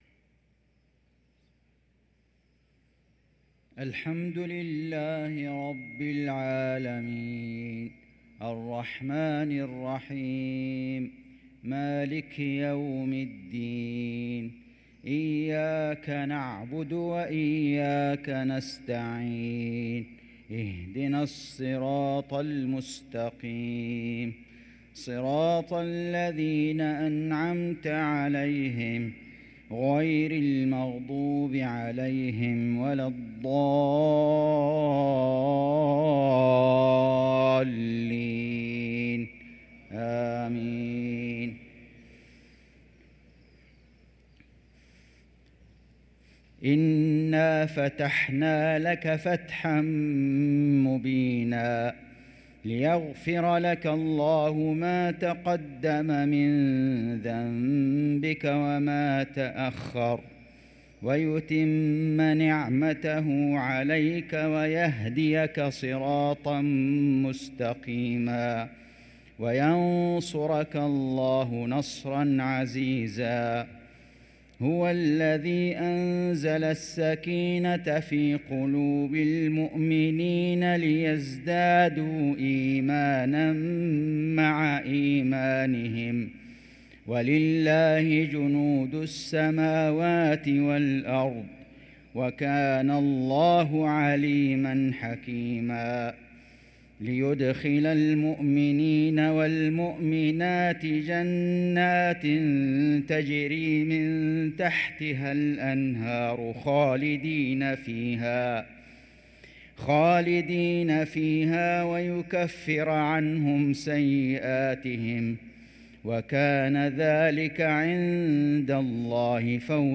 صلاة المغرب للقارئ فيصل غزاوي 7 ربيع الآخر 1445 هـ
تِلَاوَات الْحَرَمَيْن .